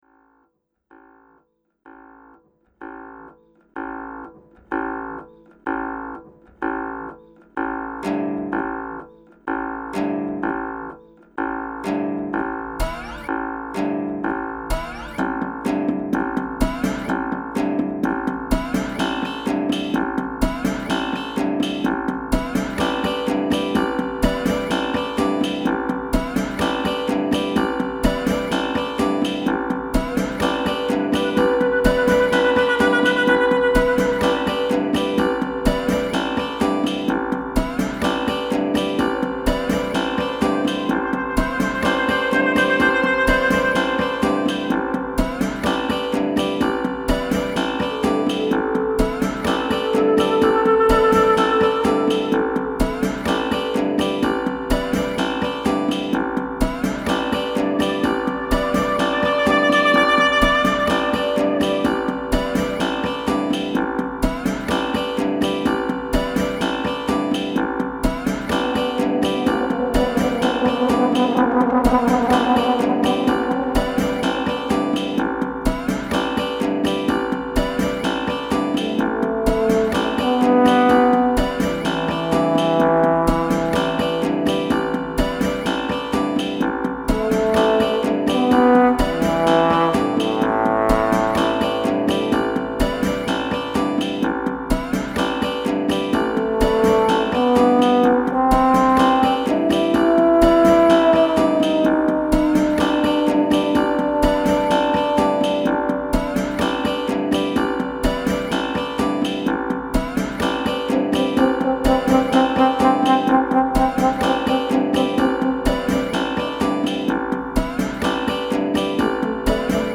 Zithermusik und Malerei
Posaune
Zither